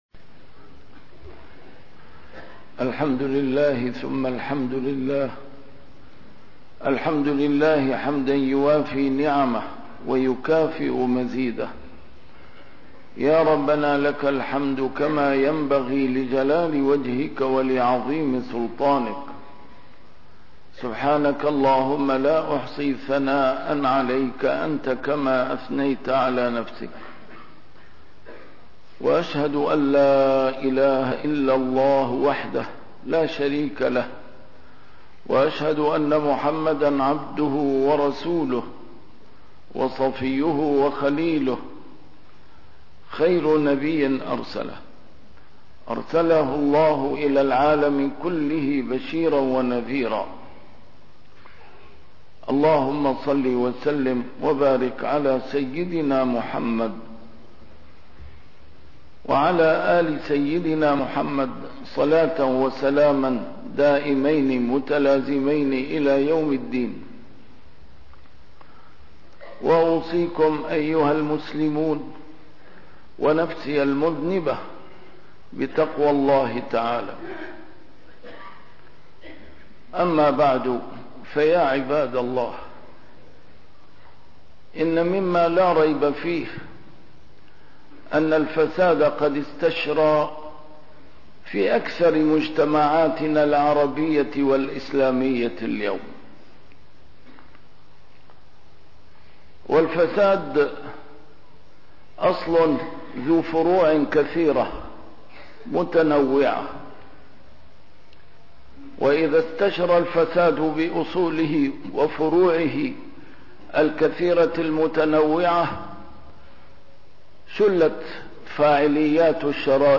A MARTYR SCHOLAR: IMAM MUHAMMAD SAEED RAMADAN AL-BOUTI - الخطب - أهمية تفعيل مناهج التربية الإسلامية في مناهجنا